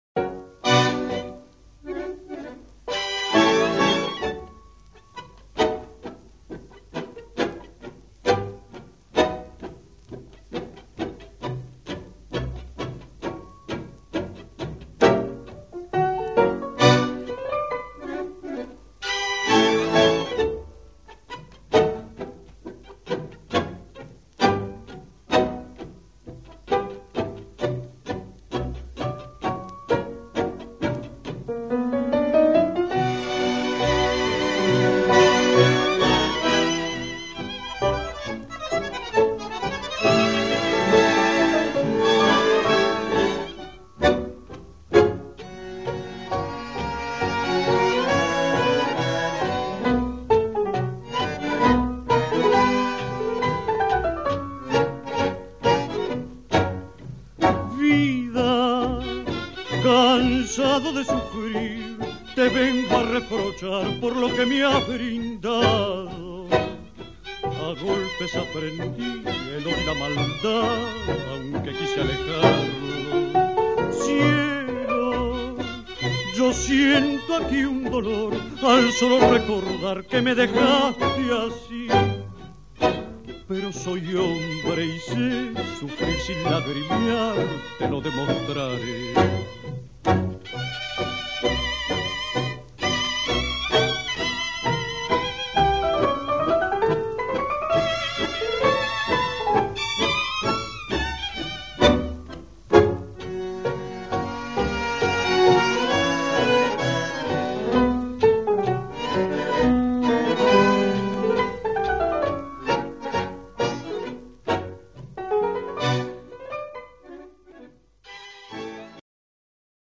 Pozdravljeni ljubitelji dobre tango glasbe!